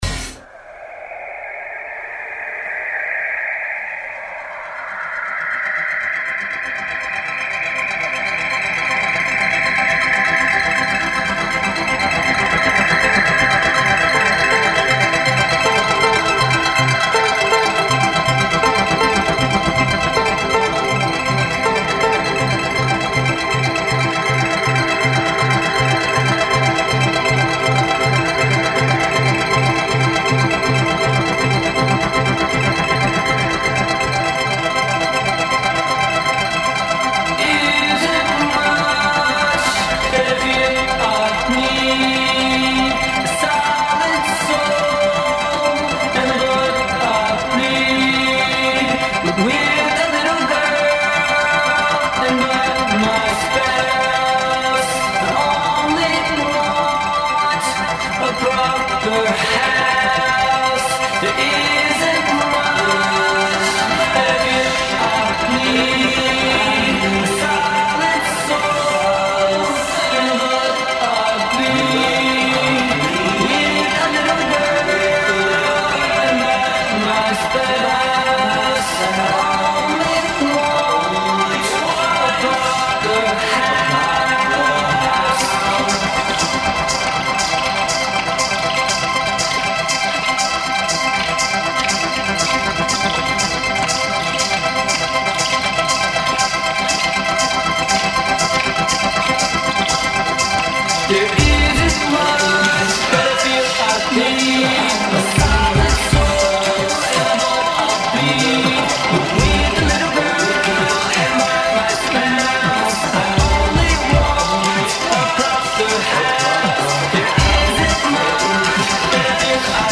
La musica come unico raccordo capace di intrecciare più racconti.